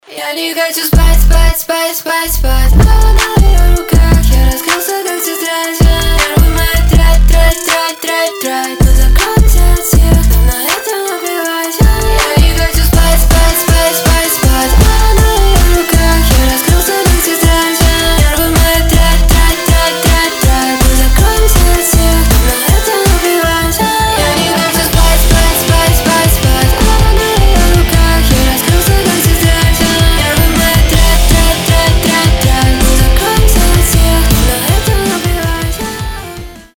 • Качество: 320, Stereo
громкие
электронные